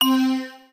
Universal UI SFX / Clicks
UIClick_Mallet Tonal Long 03.wav